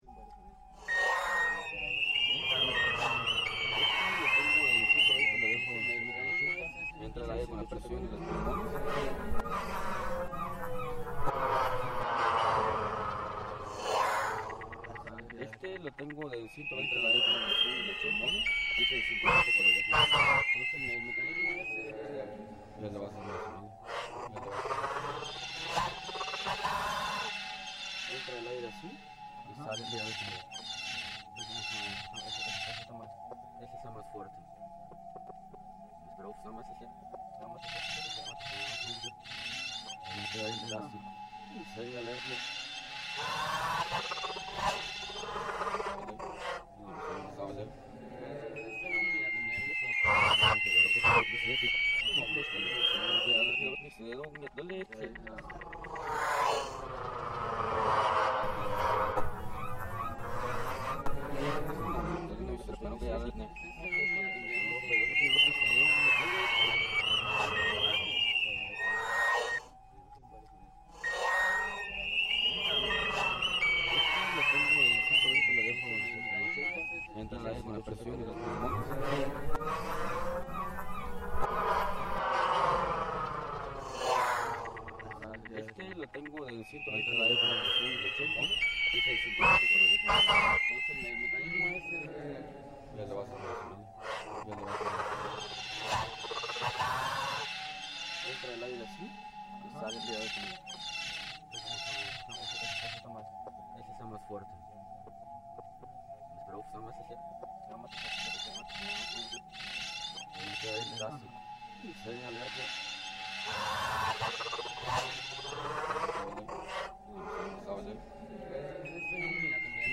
ancient Mayan instruments which sound like bird calls